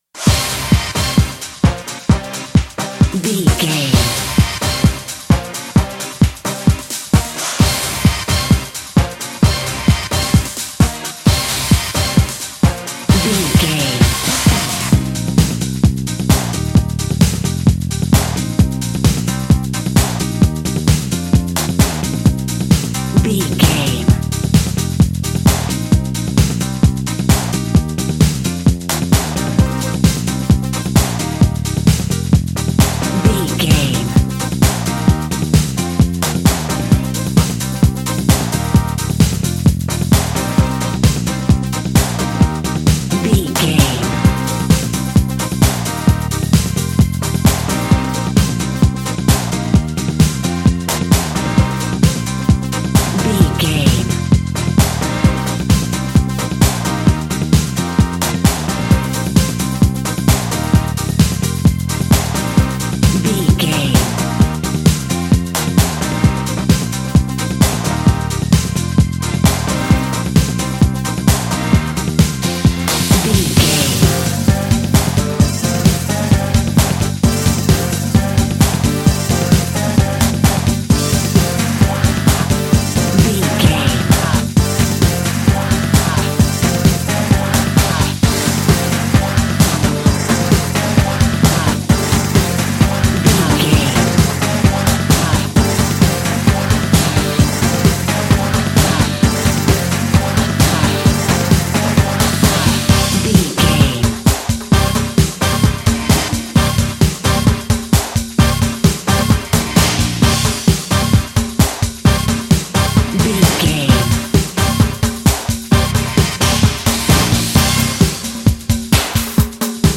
Epic / Action
Fast paced
Aeolian/Minor
Fast
synthesiser
drum machine
Eurodance